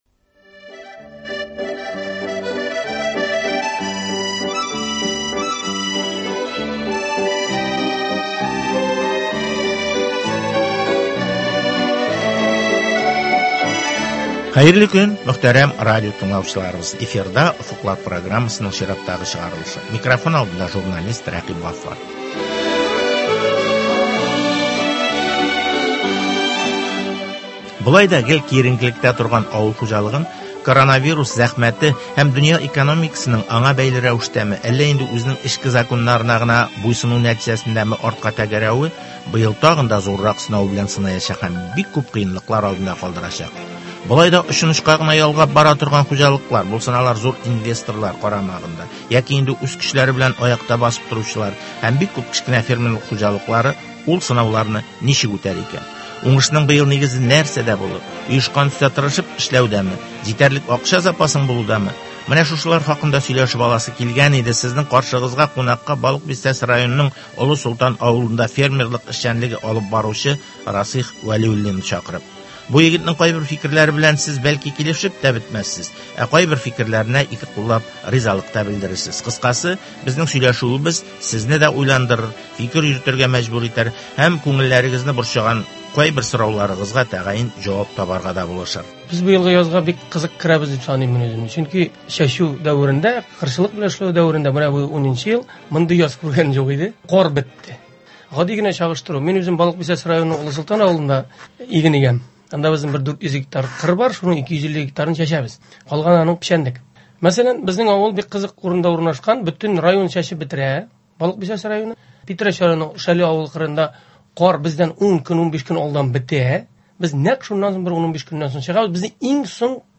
сөйләшү.